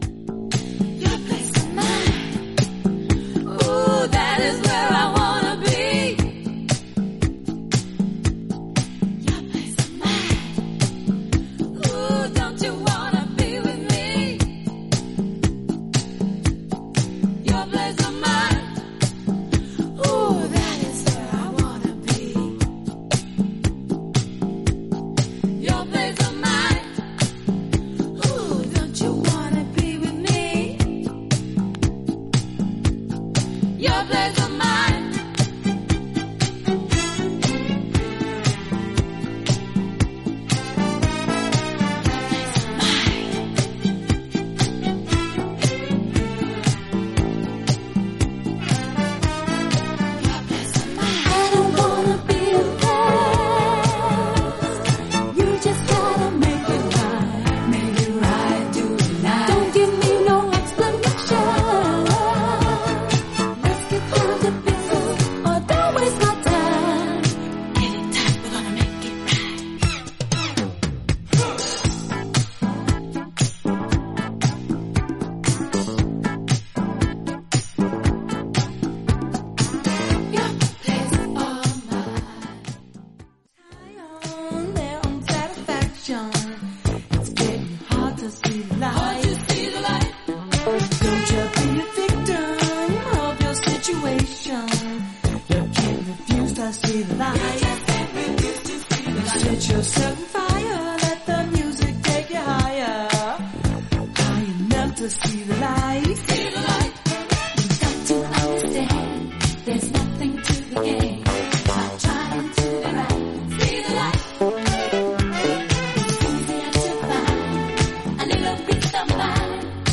中毒性のあるミニマルなベースが牽引する、軽快でアーバンな雰囲気の80Sディスコ・ナンバー
ファンクネス感のあるベースライン、ホーンやシンセのアレンジもナイスなディスコ・ファンク・ブギー